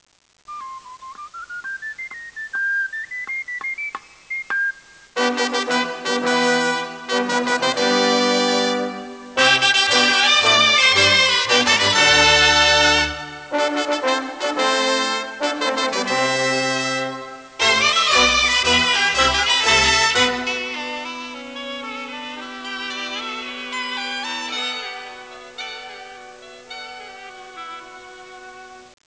sardana